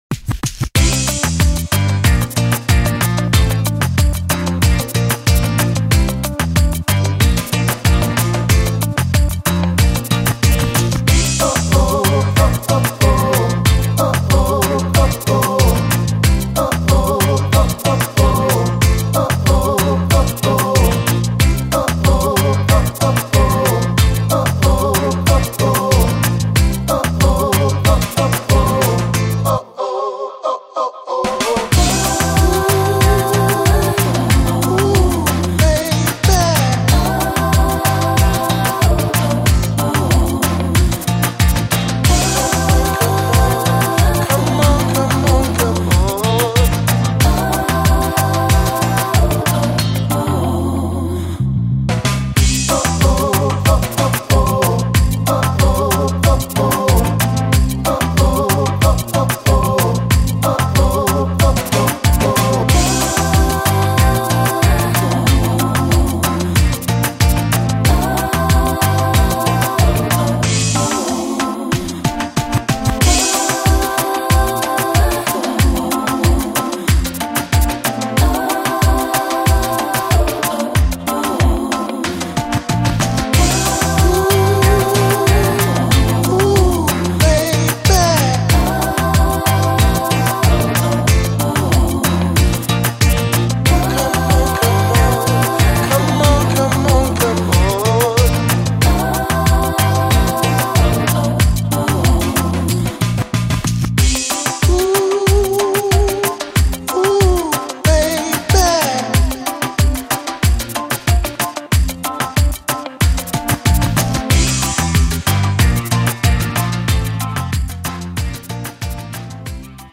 Andante [90-100] joie - voix - sexy - vacance - danse